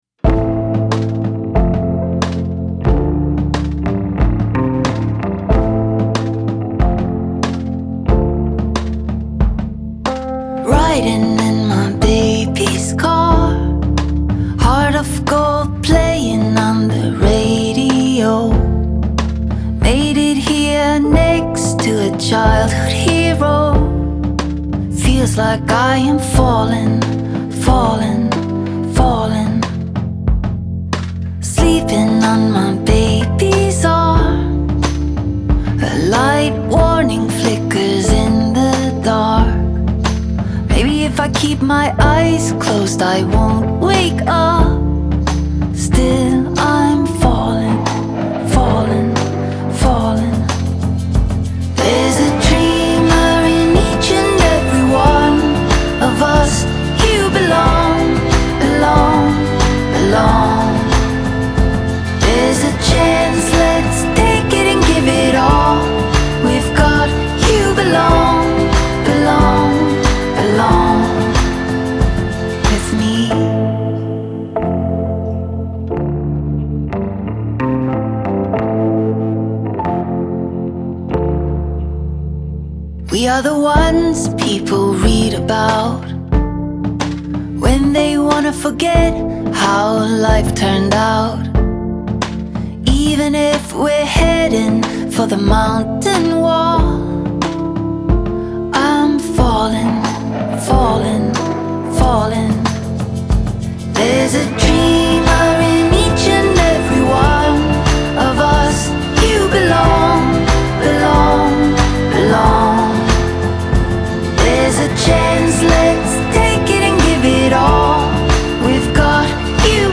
Genre : Singer & Songwriter